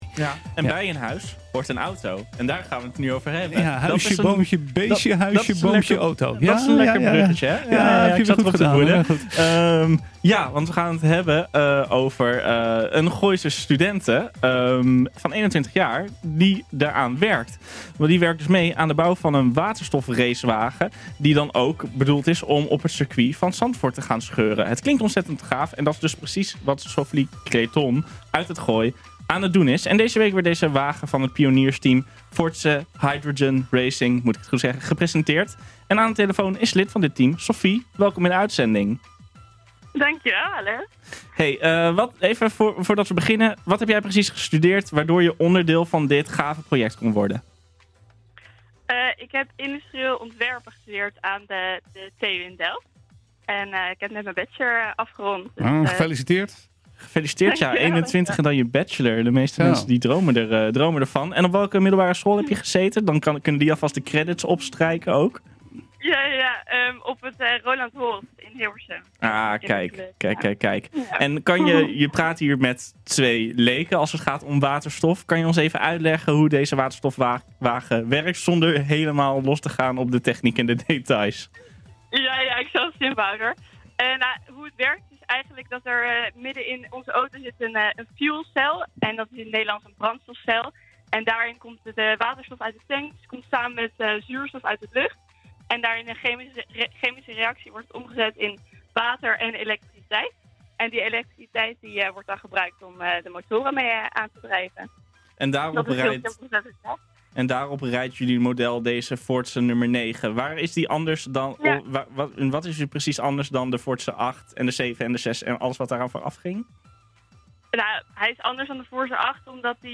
Deze week werd de wagen van het pioniersteam Forze Hydrogen Racing gepresenteerd. Aan de telefoon lid van het team